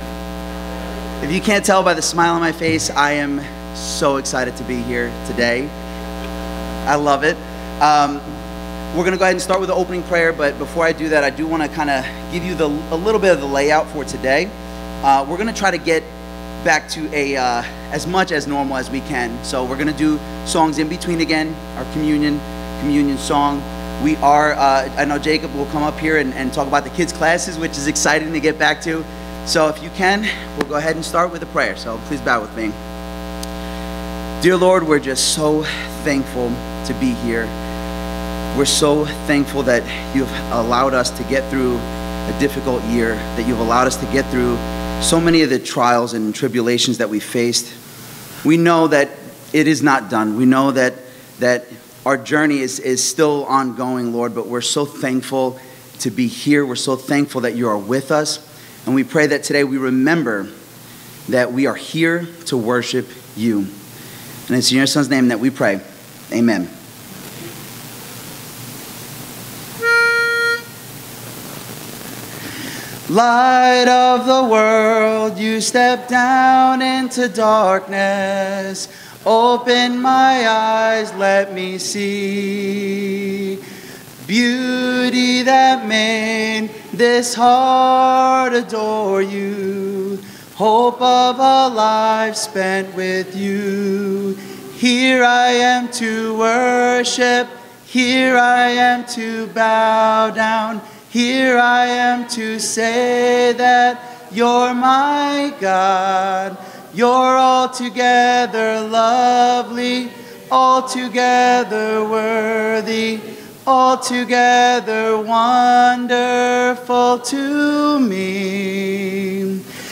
Please note that the buzzing sound at the beginning of the recording clears up in 45 seconds. This was recorded at the live service on March 7, 2021.
Sermon Audio: Download